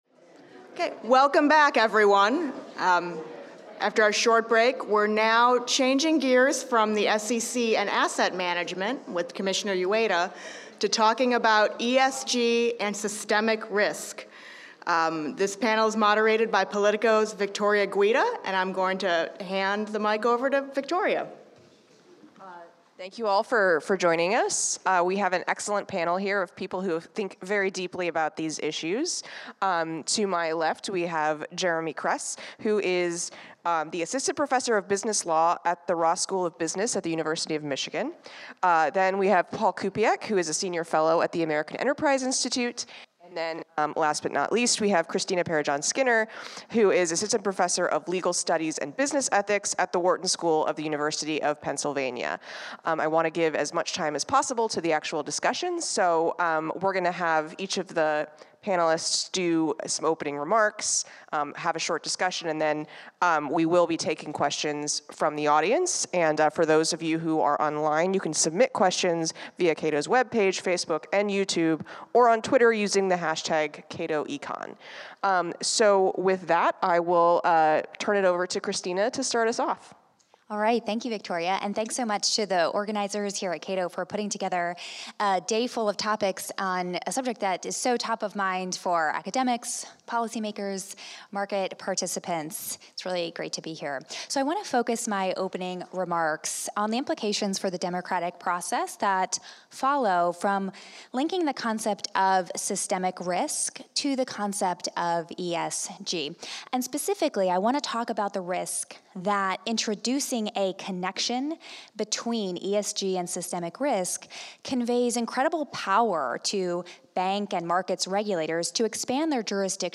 Join us for an outstanding program featuring leading policymakers and experts discussing ESG and the future of financial regulation at Cato’s eighth annual Summit on Financial Regulation.